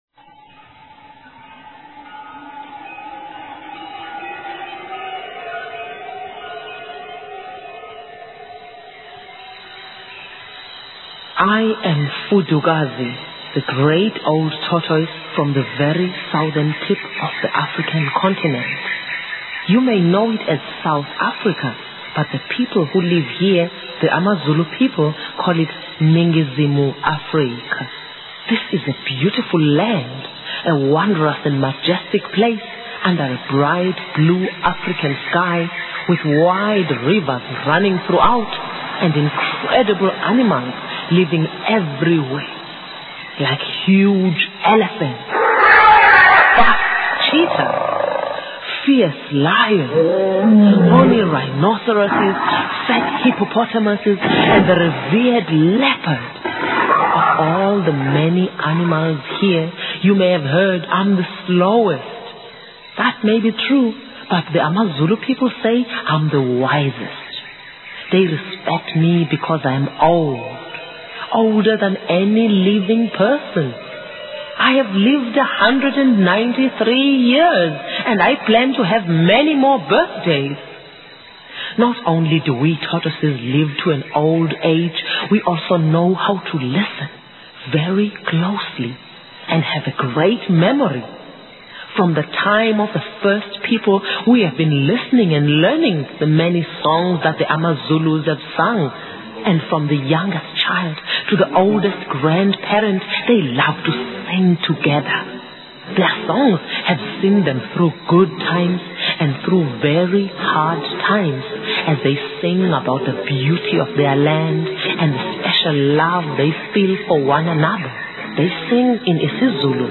Listen to the story teller while you read along.